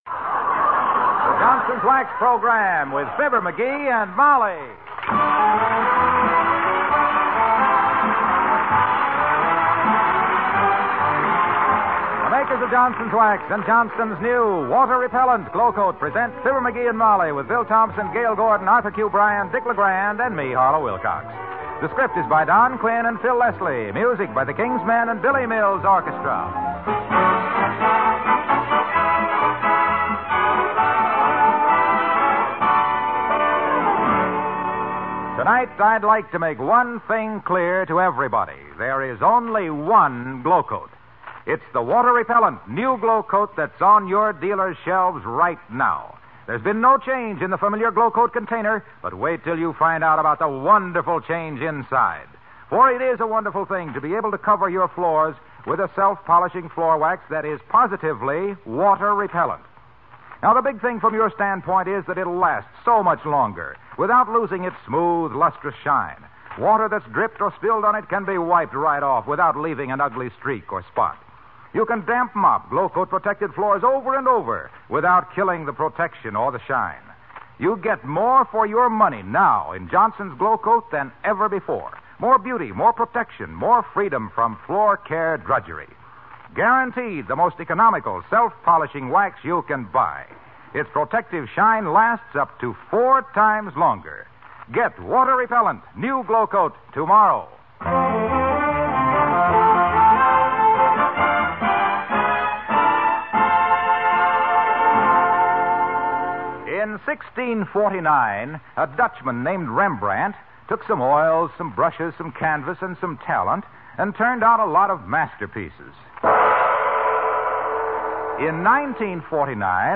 Fibber McGee and Molly was an American radio comedy series.
The title characters were created and portrayed by Jim and Marian Jordan, a real-life husband and wife team that had been working in radio since the 1920s.